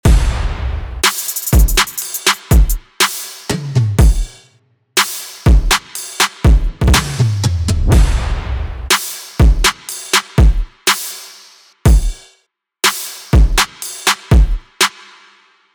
FUTURE BASS/EDM SERUM PRESETS
‘Repeater’ Drum Loop
Repeater-Drum-Loop.mp3